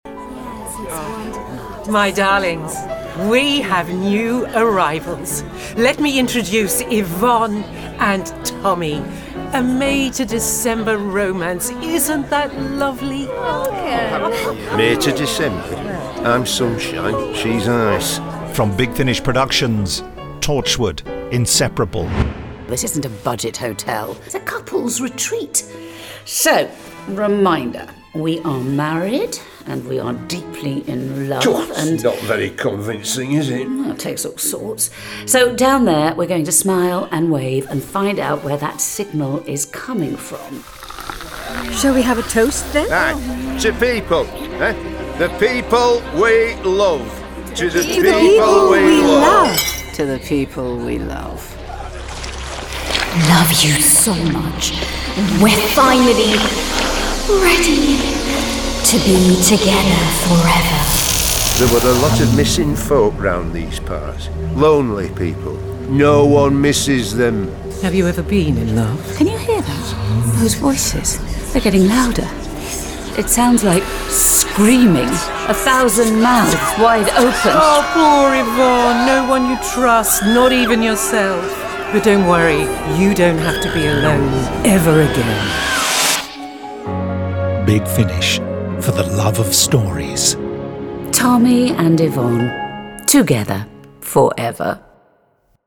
Torchwood: Inseparable Released February 2025 Written by Malcolm Devlin Helen Marshall Starring Tracy-Ann Oberman Timothy Bentinck This release contains adult material and may not be suitable for younger listeners. From US $11.61 CD + Download US $14.19 Buy Download US $11.61 Buy Save money with a bundle Login to wishlist 5 Listeners recommend this Share Tweet Listen to the trailer Download the trailer